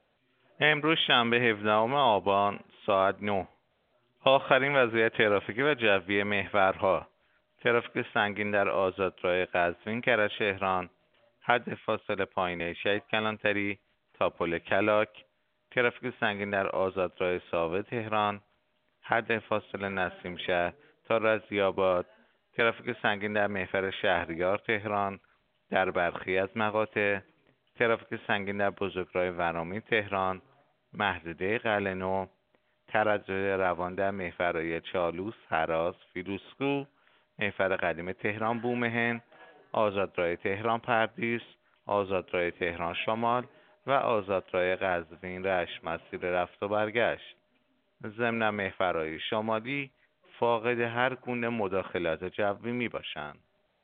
گزارش رادیو اینترنتی از آخرین وضعیت ترافیکی جاده‌ها ساعت ۹ هفدهم آبان؛